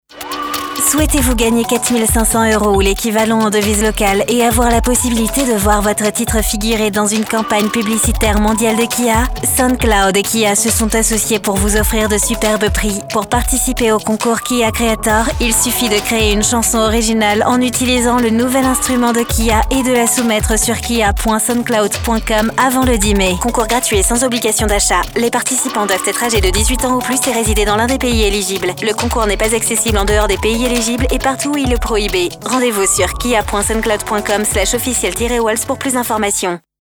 Female – French, Modern, Chill